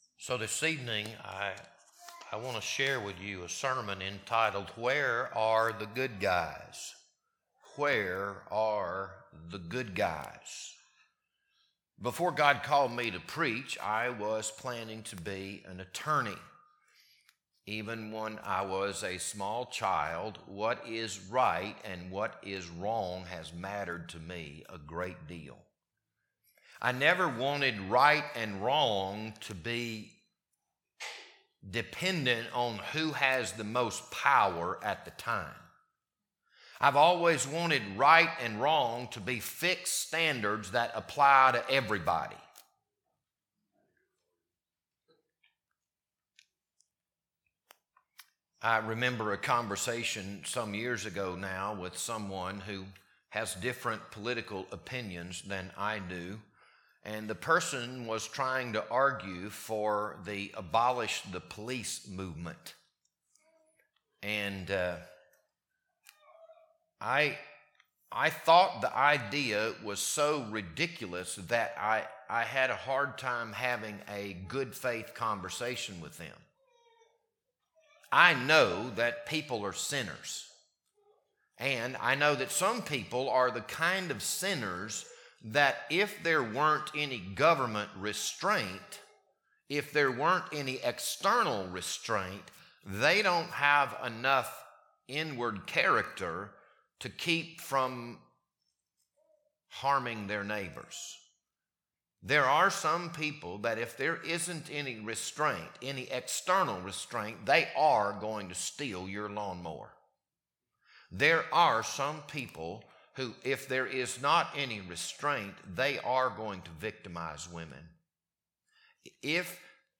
This sermon was recorded for April 3rd, 2026, Good Friday.